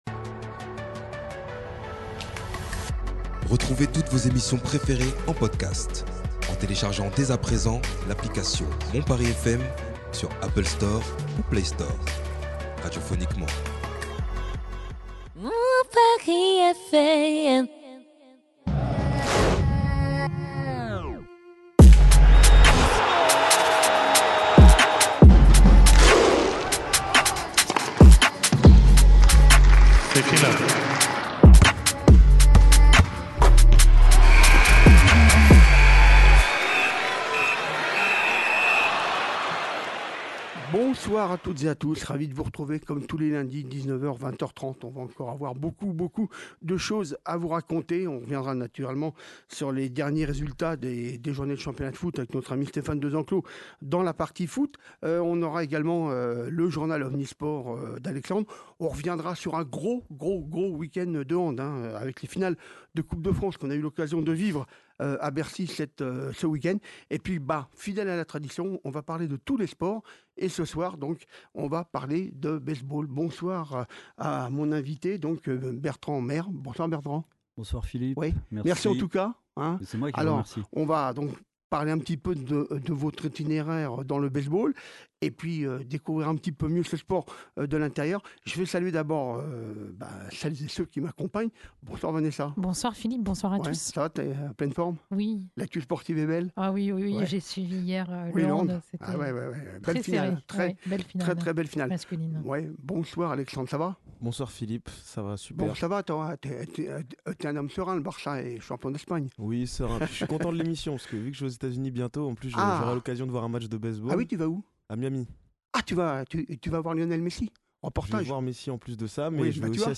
Nous reviendrons aussi, avec plusieurs interviews, sur les finales de Coupe de France de Handball disputées ce week-end à l’Accor Aréna de Bercy